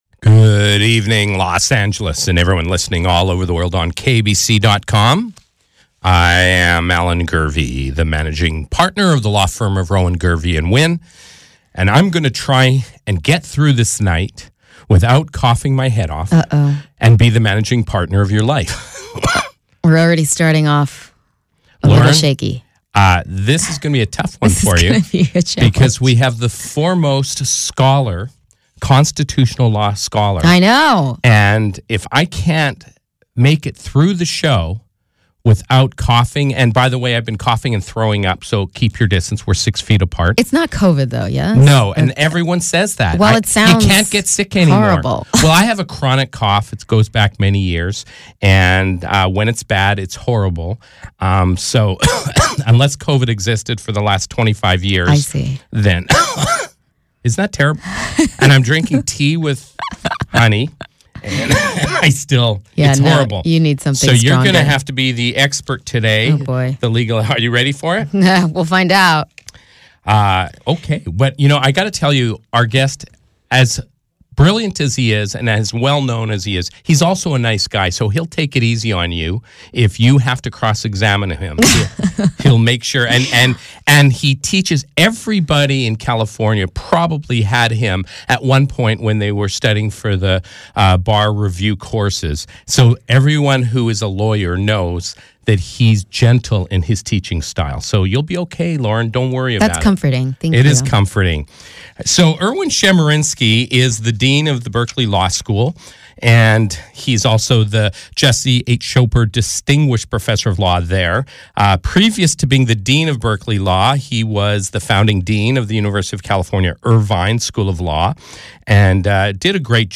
This episode features an interview with UC Berkeley Law School Dean Erwin Chemerinsky on vaccine mandates, the latest Roe v. Wade challenge at the Supreme Court, and his new book " Presumed Guilty: How the Supreme Court Empowered the Police and Subverted Civil Rights ."